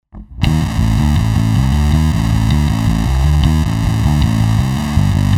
mufffuzz2.mp3